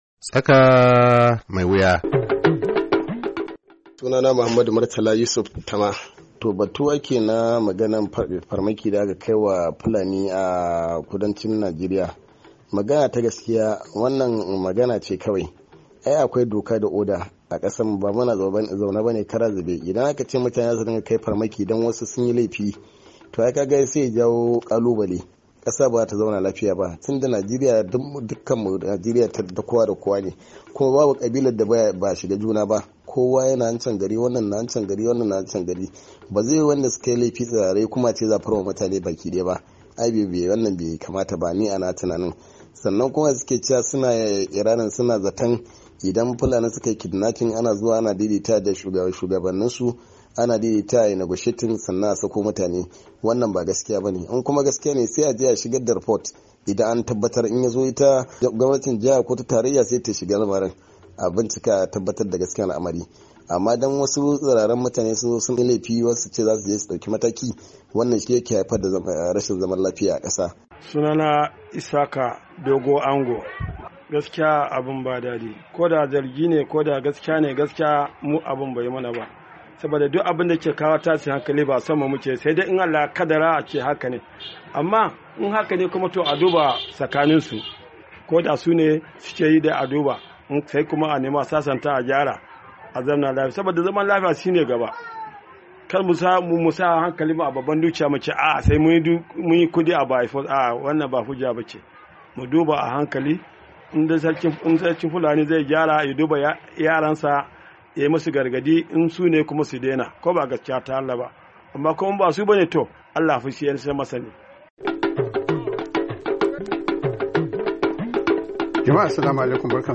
TSAKA MAI WUYA: Muhawara Kan Rikicin Fulani a Najeriya - 11'32